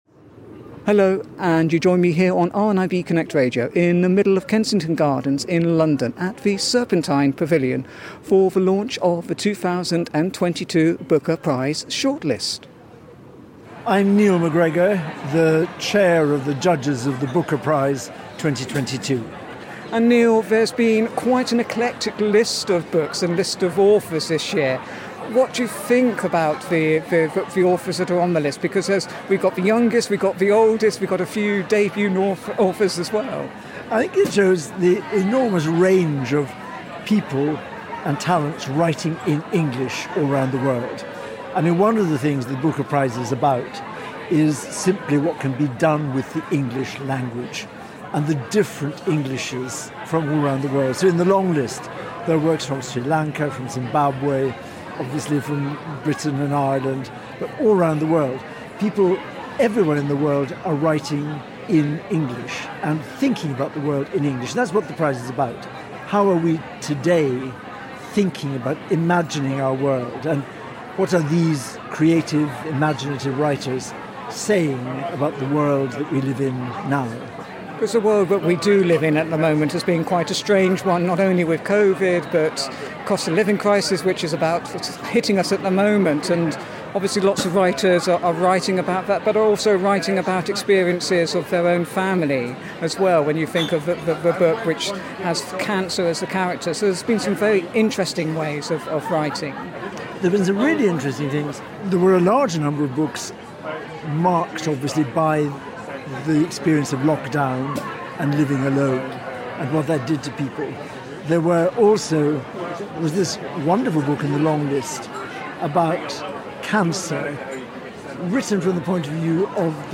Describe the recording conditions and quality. The 2022 Booker Prize Short List was announced at 7pm on Tuesday 6 September 2022 at the Serpentine Pavilion, Kensington Gardens, central London